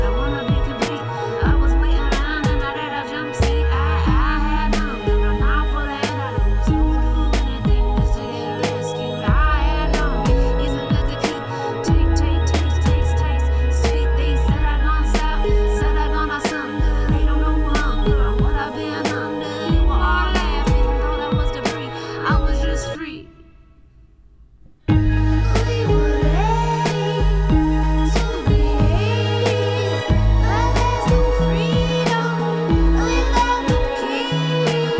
Жанр: Соундтрэки